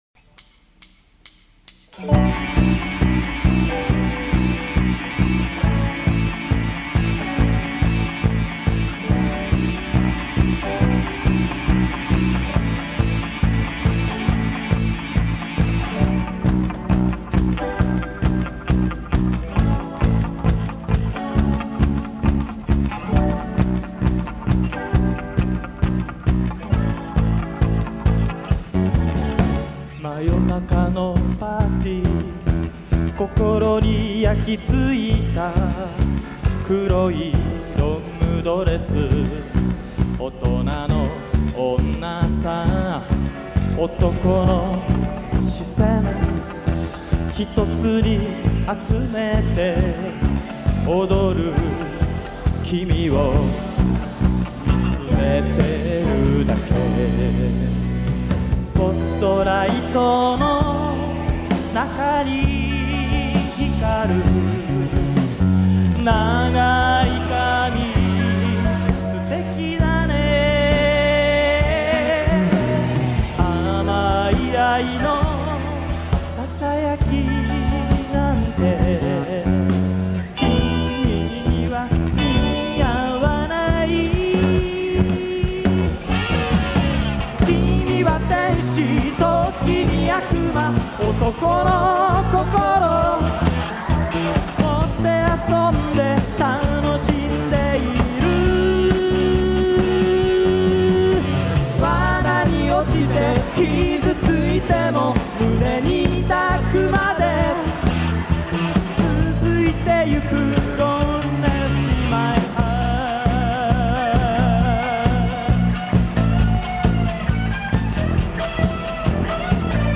1985年9月22日に行われた記念コンサート
TOAST「Loneliness In My Heart」